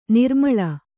♪ nirmaḷa